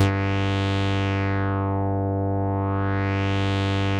Index of /90_sSampleCDs/Trance_Explosion_Vol1/Instrument Multi-samples/Wasp Dark Lead
G2_wasp_dark_lead.wav